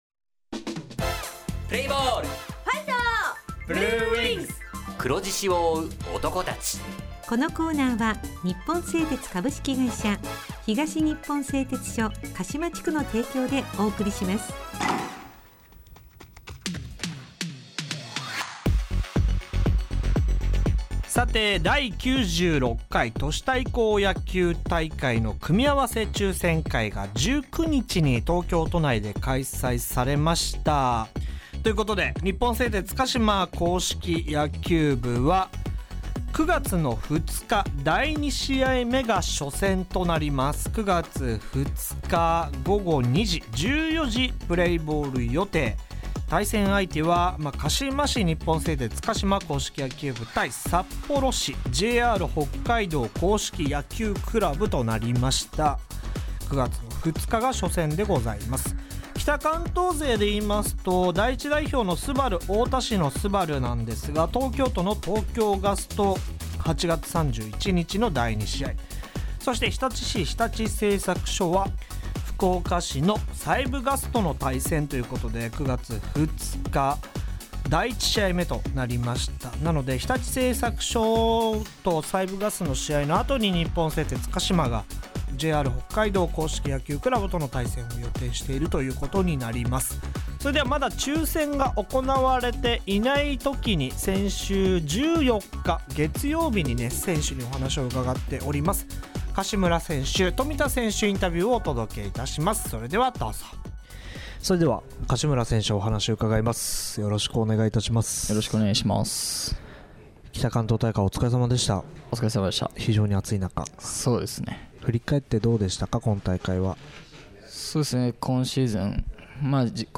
地元ＦＭ放送局「エフエムかしま」にて鹿島硬式野球部の番組放送しています。
《選手インタビュー》